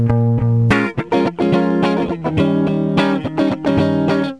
Le guitariste électrique - Exercice de rythmique 1
Ecoutez le rythme Maintenant, essayez de le rejouez en boucle.